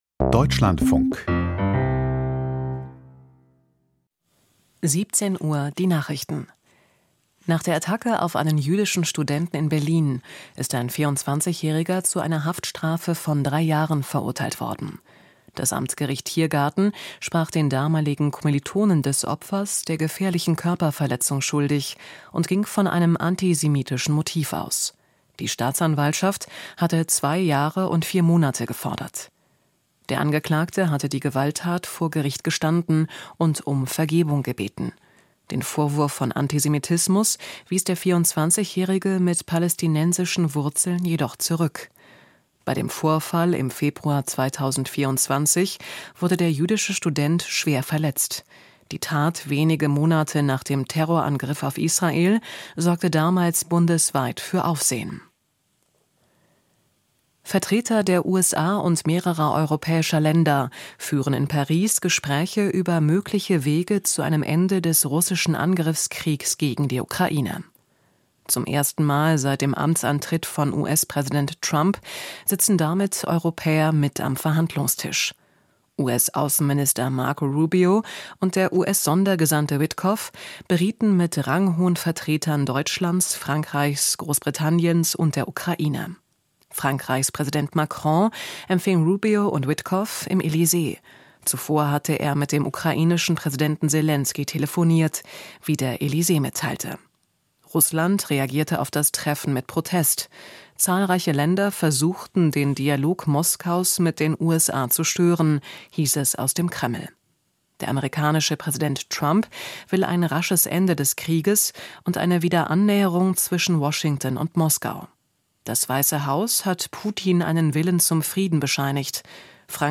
Treffen gut und vielversprechend? - Interview